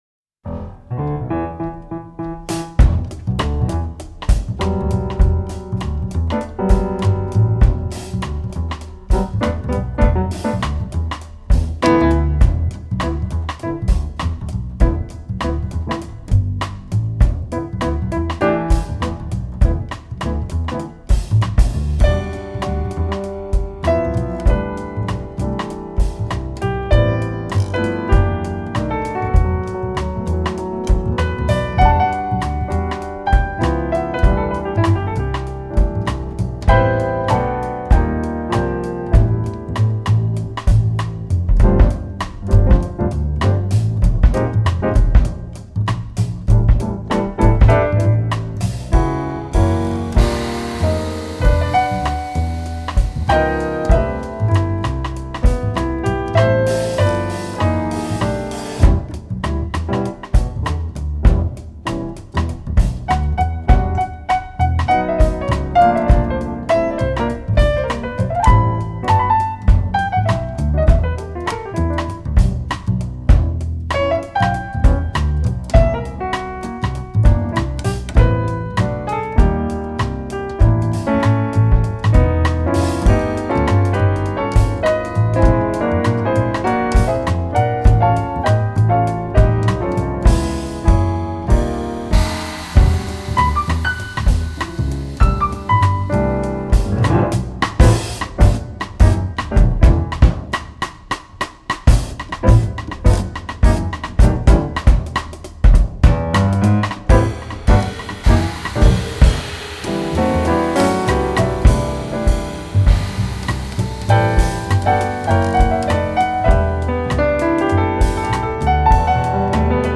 Inscrit dans la plus pure tradition du swing
contrebasse
batterie